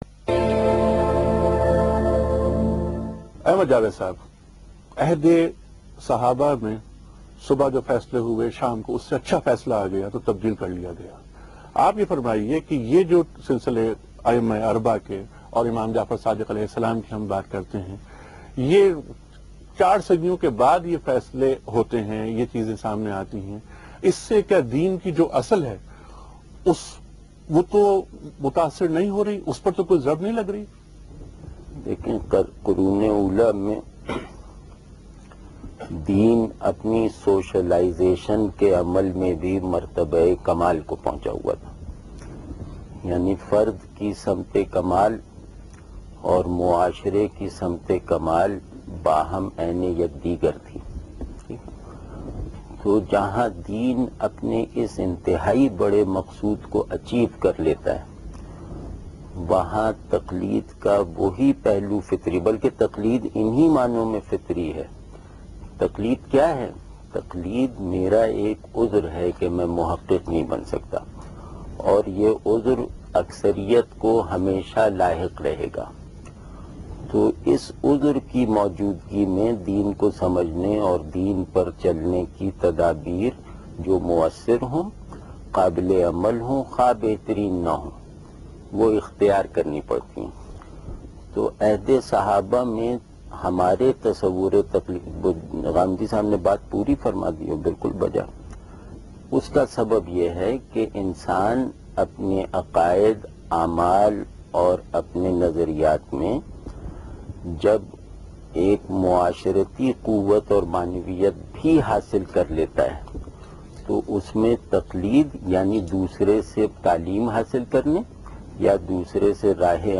Category: TV Programs / Geo Tv / Alif /